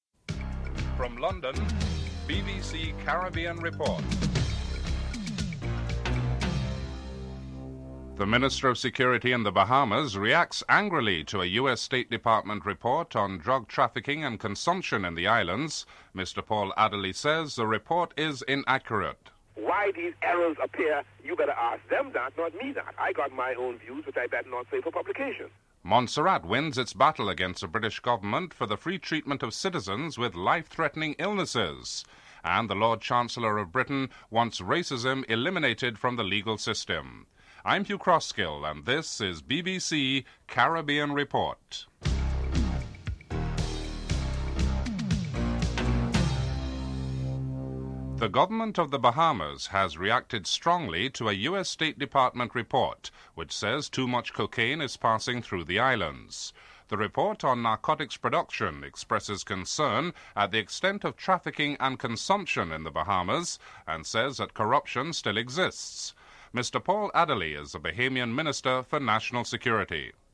6. Review of what the British press has been saying this week that are of interest to the Caribbean (11:11-15:00)